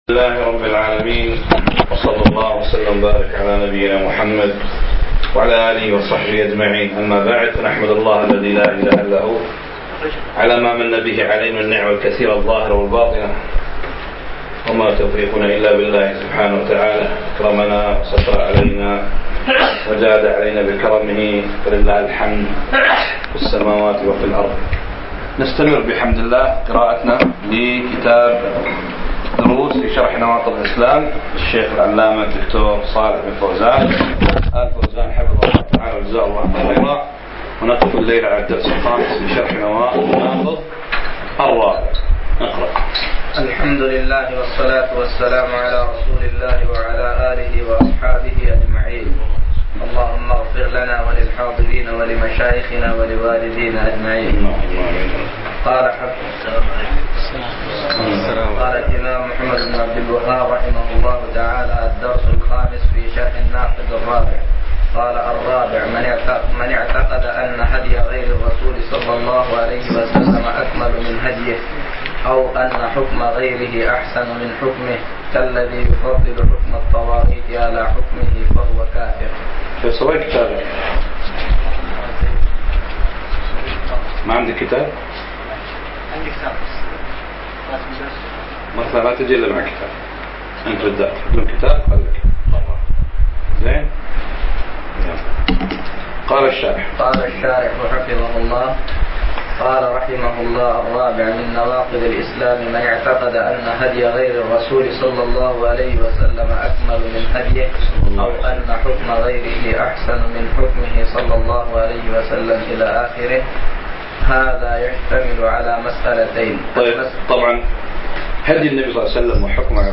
الدرس 18 - دروس في شرح نواقض الاسلام